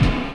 0208 DR.LOOP.wav